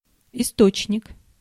Ääntäminen
IPA : /ˈɹɛf.ɹəns/ IPA : /ˈɹɛf.ɜː(ɹ).əns/